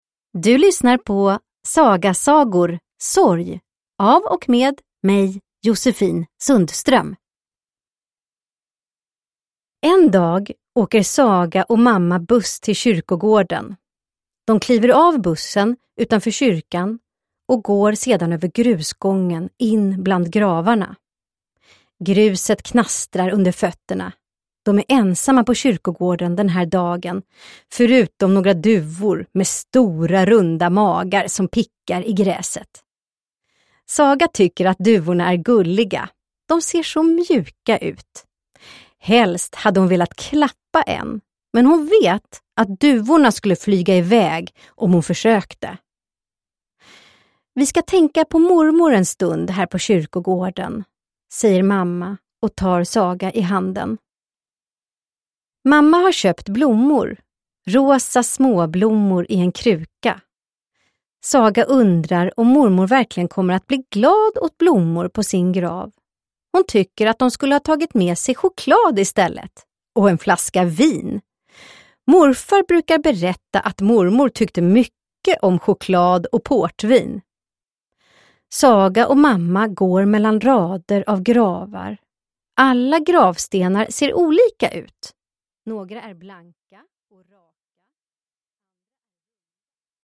Sorg – Ljudbok – Laddas ner